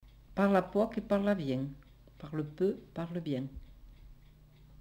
Aire culturelle : Comminges
Lieu : Cathervielle
Effectif : 1
Type de voix : voix de femme
Production du son : récité
Classification : proverbe-dicton